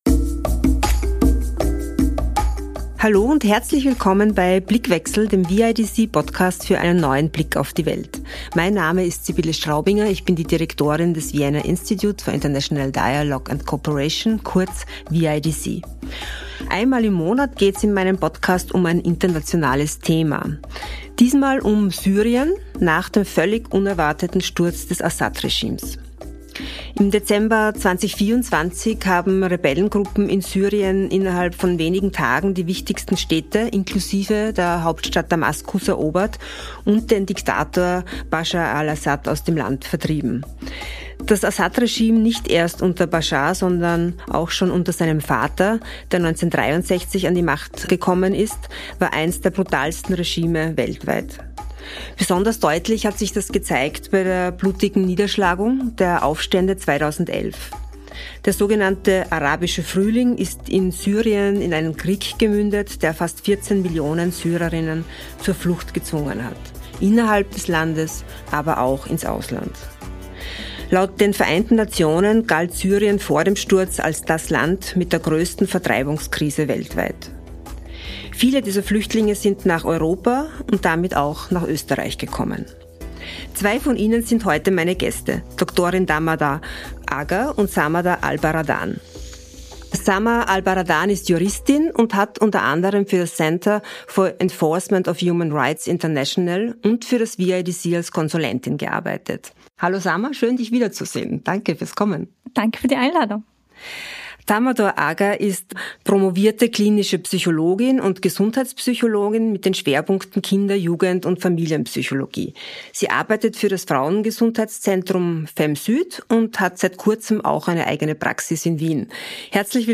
Wie blicken sie heute auf ihr Heimatland? Ein Gespräch über Flucht, Familie und Hoffnung.